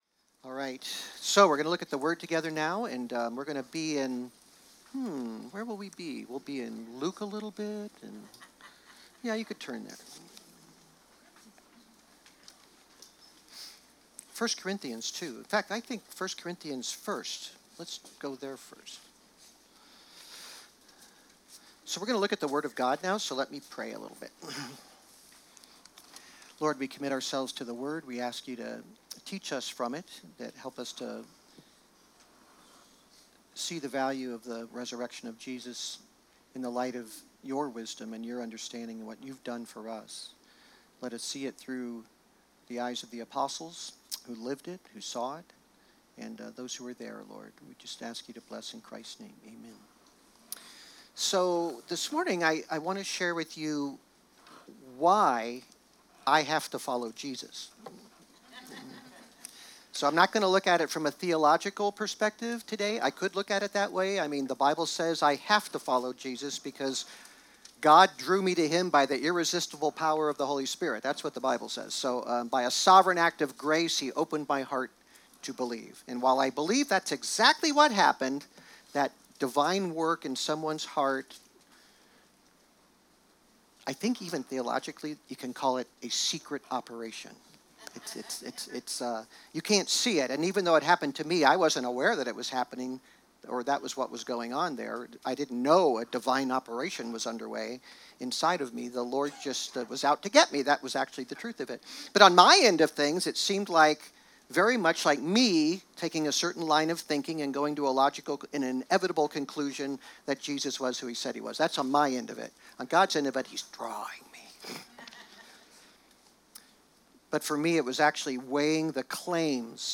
Resurrection Sunday message